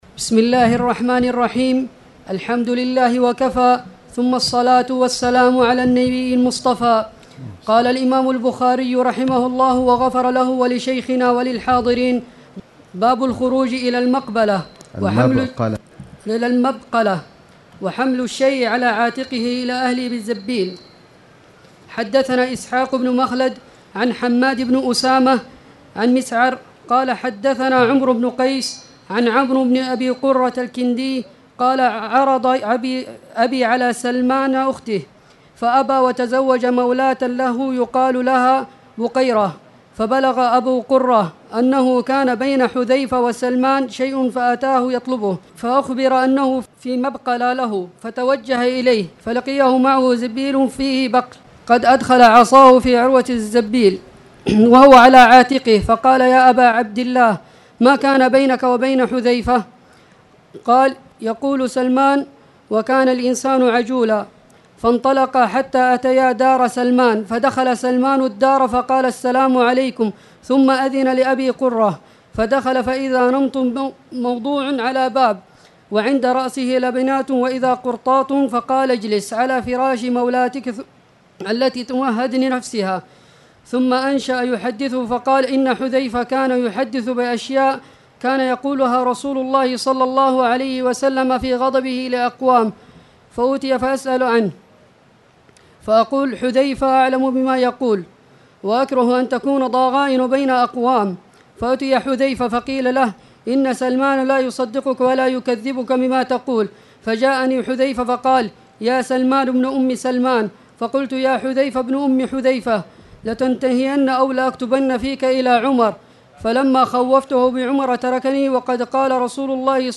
تاريخ النشر ٧ صفر ١٤٣٨ هـ المكان: المسجد الحرام الشيخ: فضيلة الشيخ د. خالد بن علي الغامدي فضيلة الشيخ د. خالد بن علي الغامدي باب الخروج إلى المبقلة